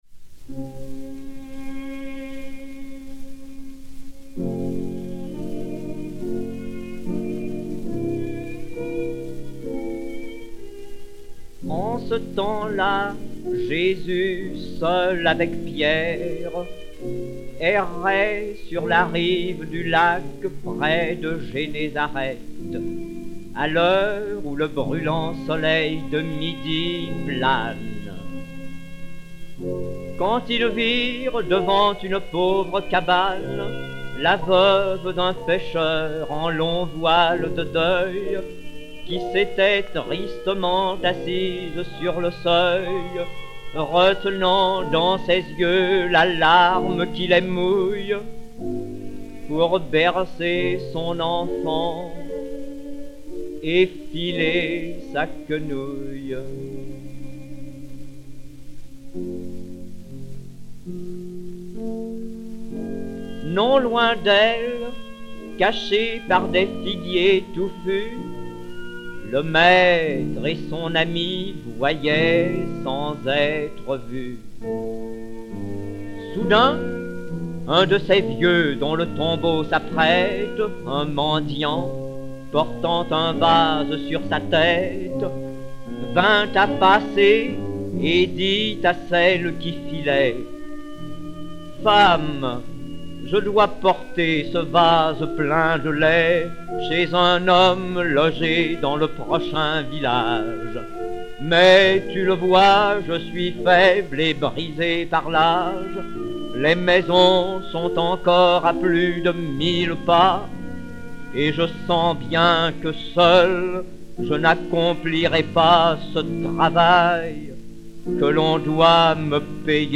(François Coppée, les Récits et les Elégies, 1878 / musique d'accompagnement de Francis Thomé)
sociétaire de la Comédie-Française et Orchestre
KI 1921-2 et KI 1922-2, enr. en 1928